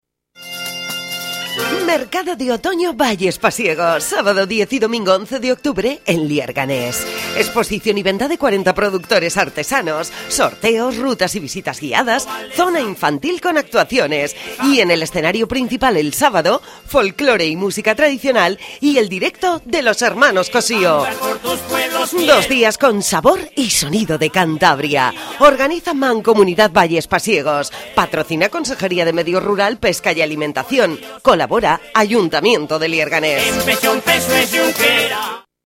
Cuña promocional